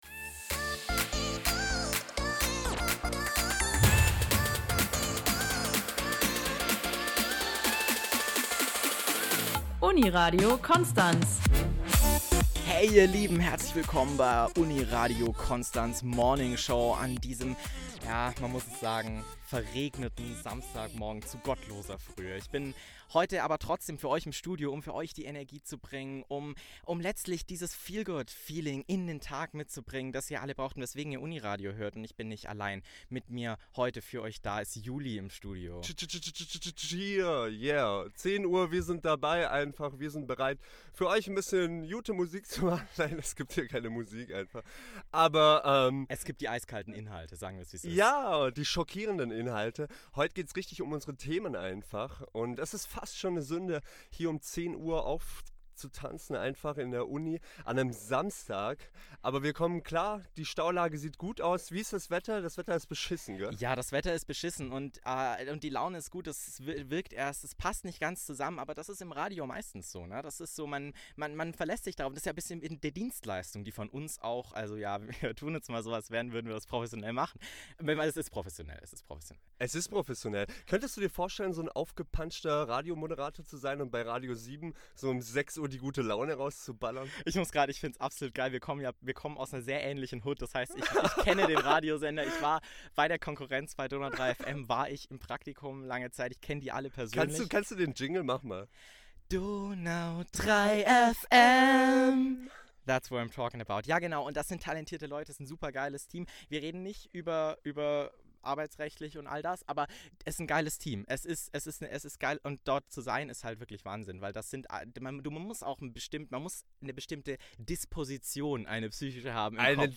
finaledit_mono_URWiSe2324F6.mp3